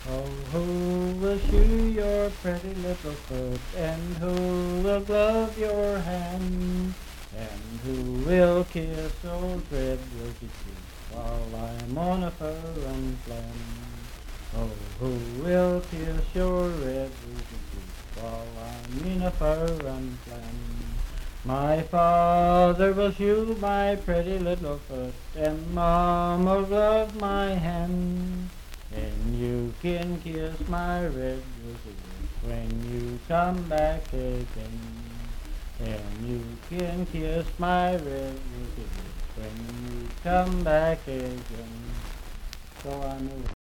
Unaccompanied vocal music
in Riverton, W.V.
Voice (sung)